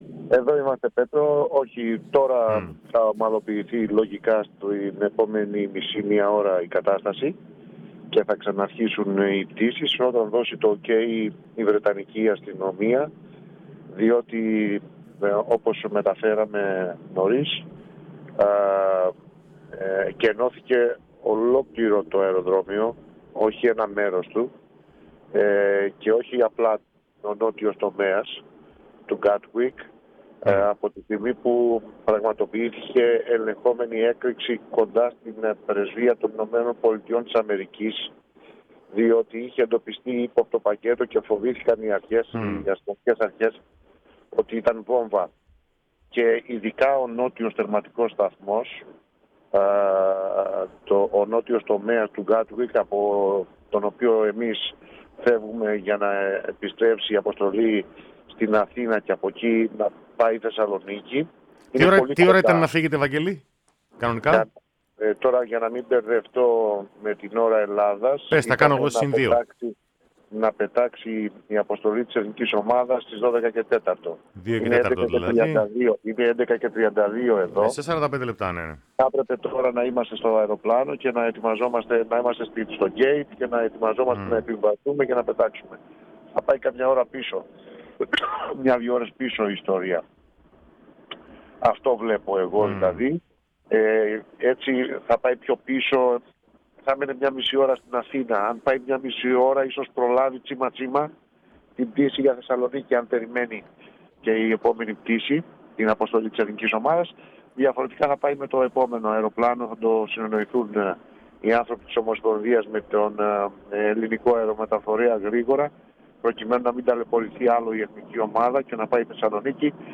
Το ρεπορτάζ από τη Μεγάλη Βρετανία: